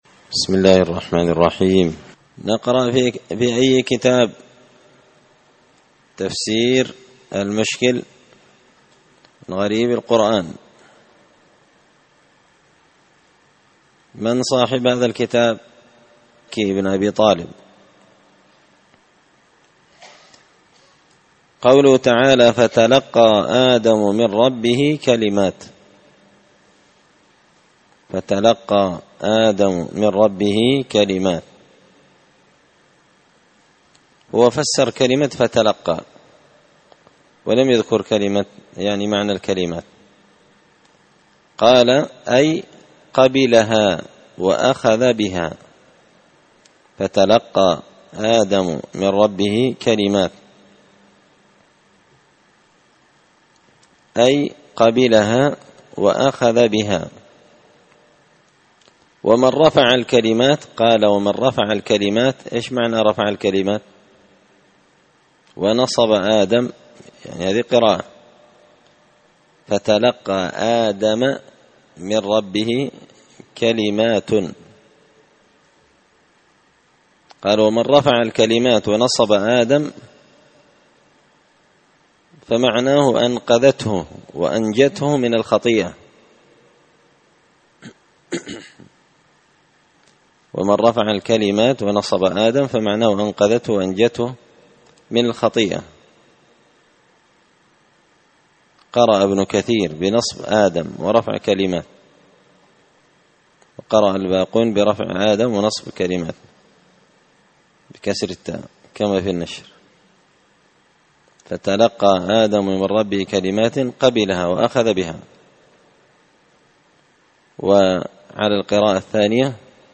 تفسير مشكل غريب القرآن ـ الدرس 10
دار الحديث بمسجد الفرقان ـ قشن ـ المهرة ـ اليمن